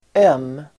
Uttal: [öm:]